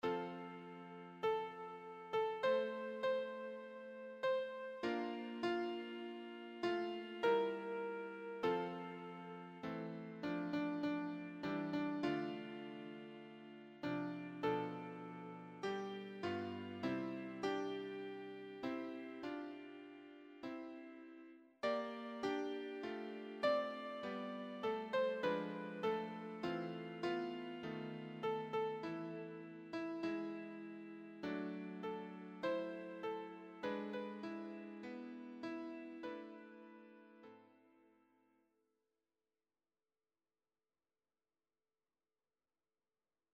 choir SATB
Anthem